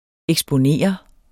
Udtale [ εgsboˈneˀʌ ]